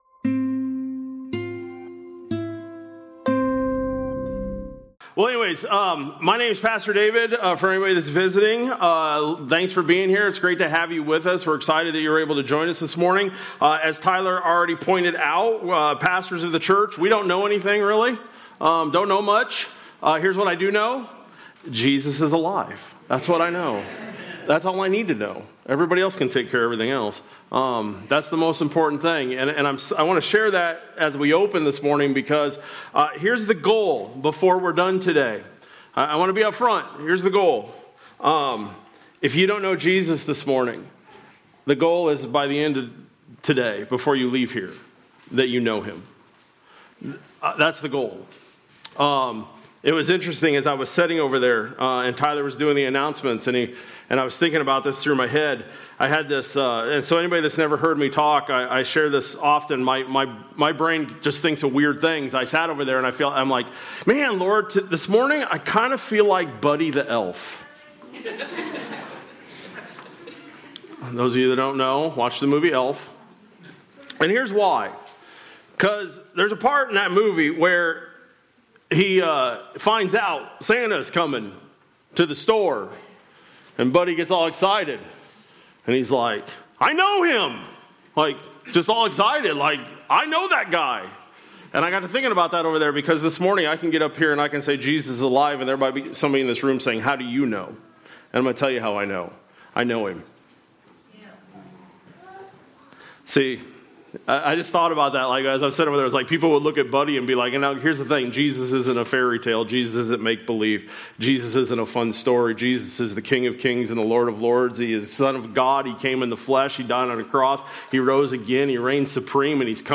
April-5-26-Sermon-Audio.mp3